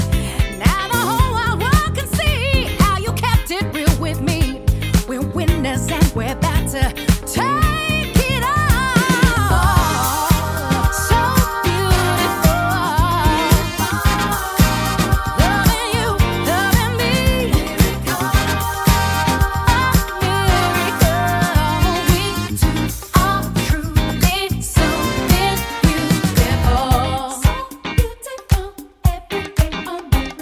chant...